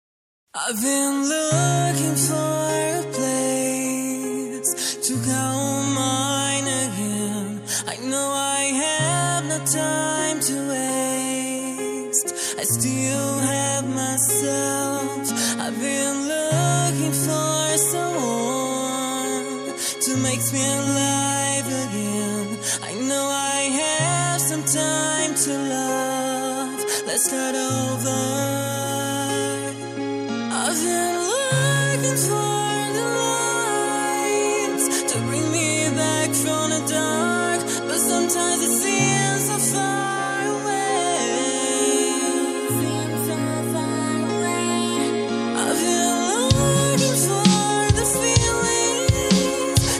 Rytmiczny beat idealny do relaksu i codziennego tła.